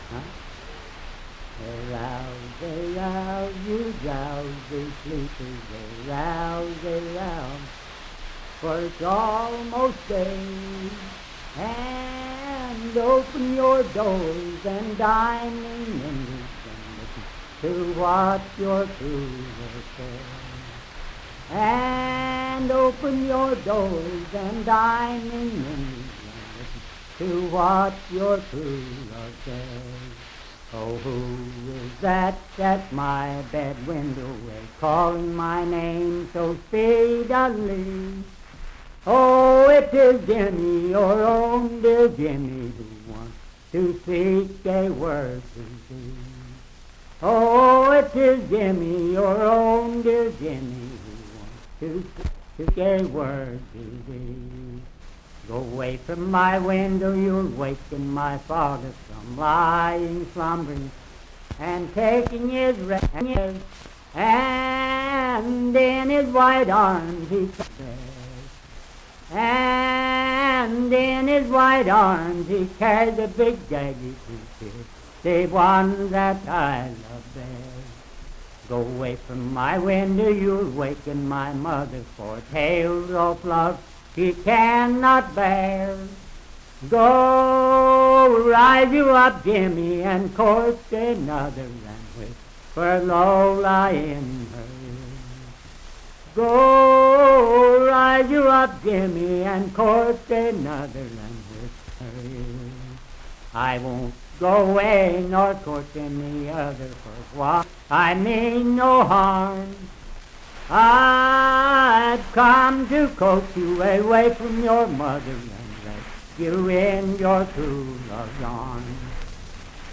Unaccompanied vocal music
Verse-refrain 6(6w/R).
Voice (sung)